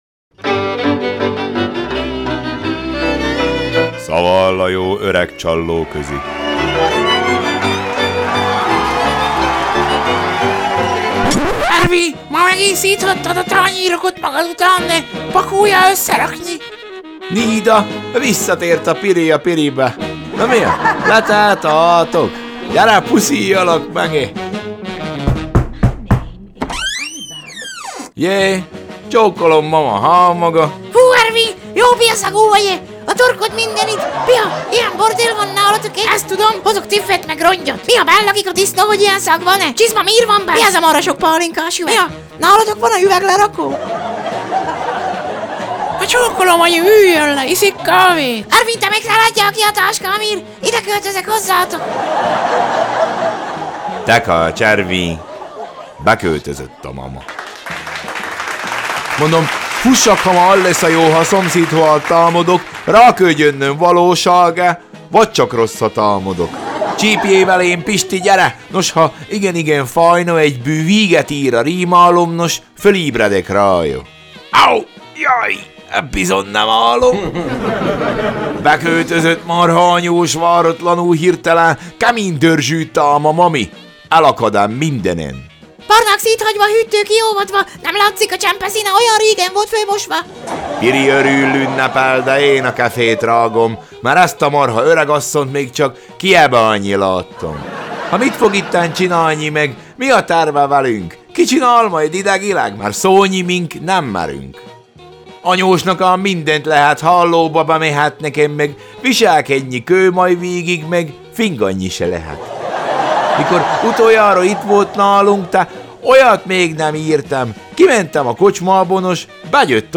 Szaval a jó öreg csallóközi Szaval a jó öreg csallóközi: Bekőtözött a mama!